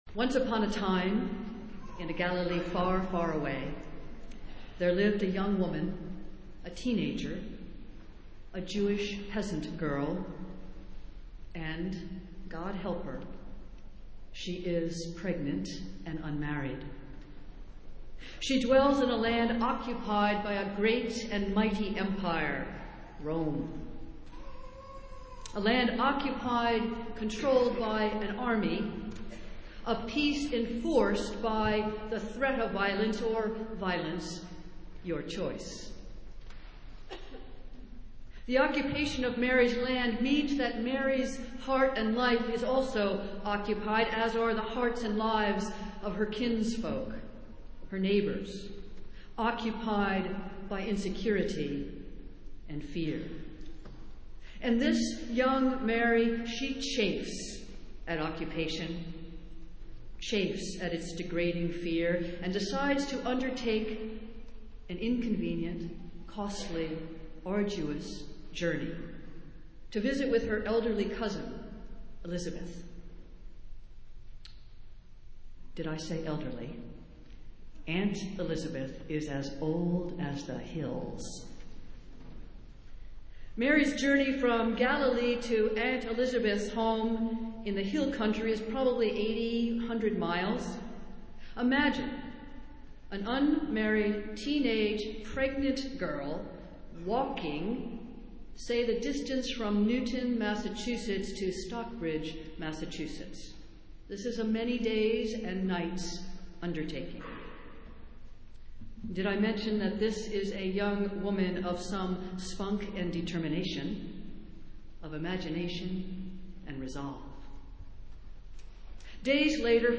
Festival Worship - Third Sunday in Advent